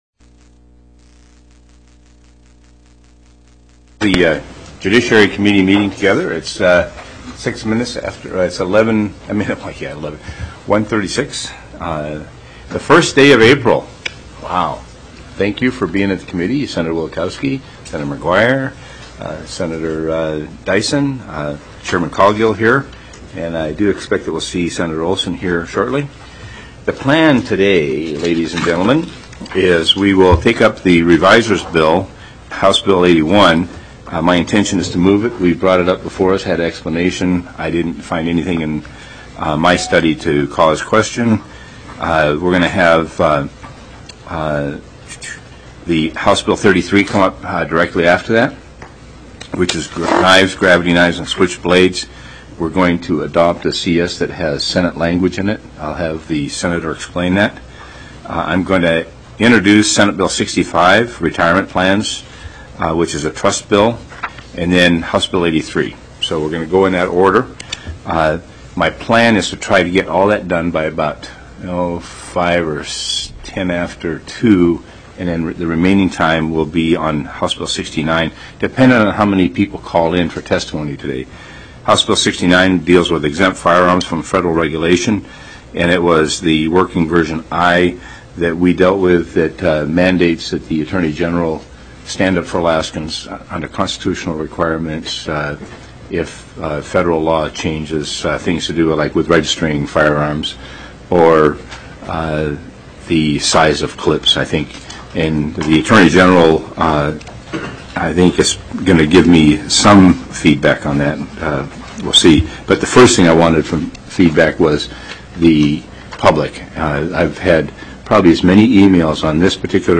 He noted that version I was before the committee and that the areas of tension were subsection (a)(1) and (2) in Section 2 and subsection (g) in Section 6. He opened public testimony.